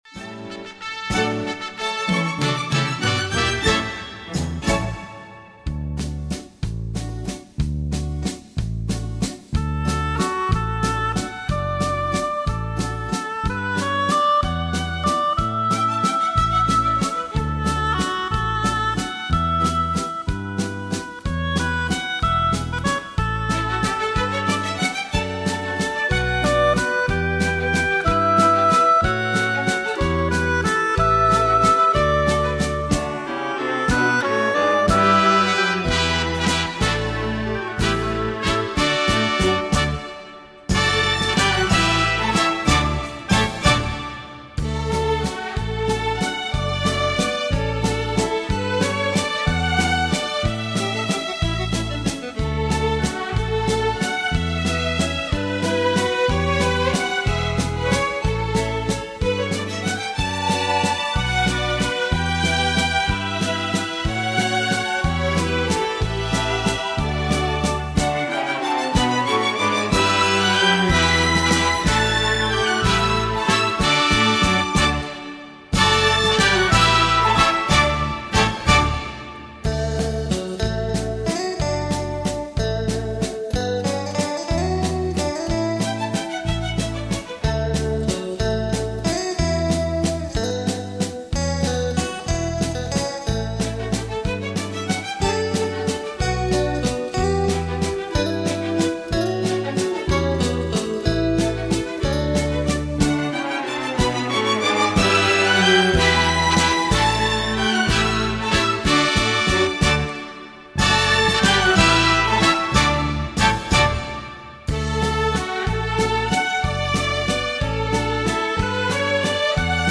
怀旧舞曲专辑
快三步